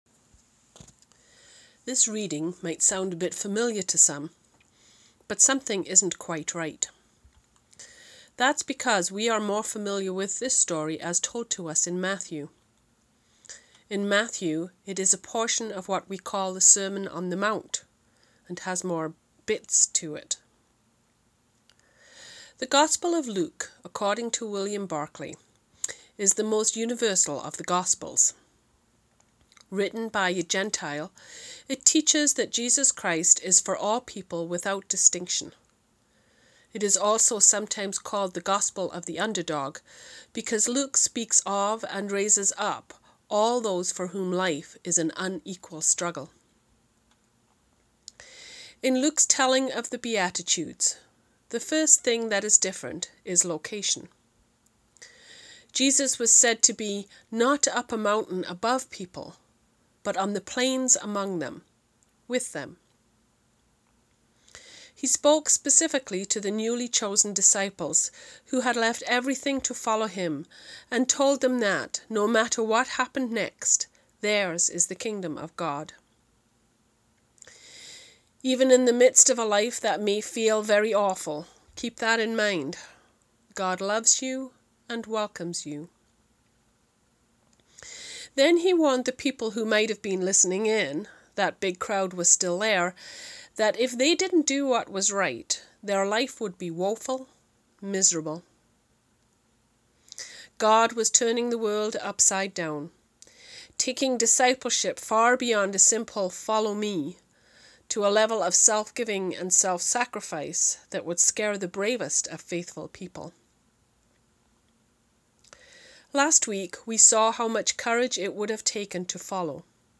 Sermons | Big Country United Churches